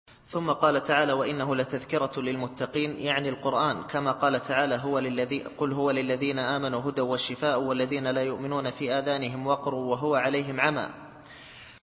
التفسير الصوتي [الحاقة / 48]